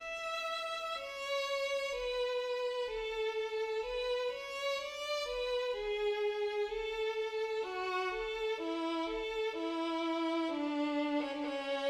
1. Allegro